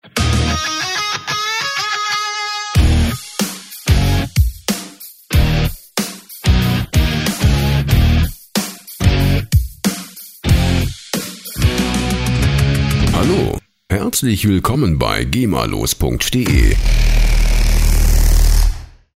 Show Opener
Musikstil: Rock
Tempo: 94 bpm